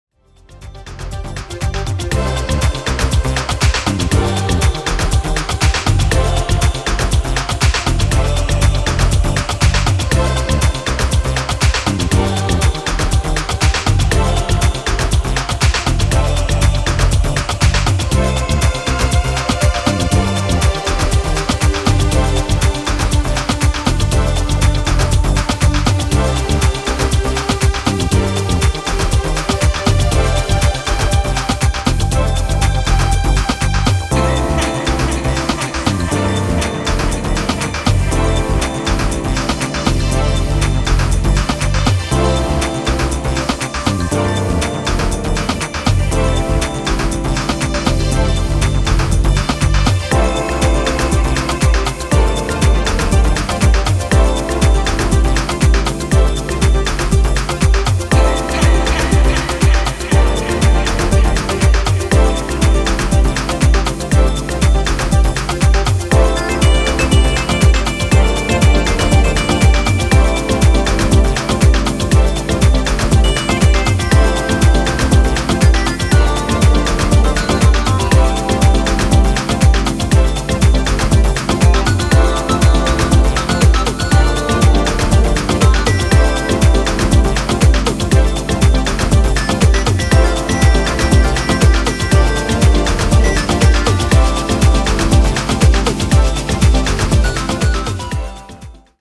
is a relentless melodic builder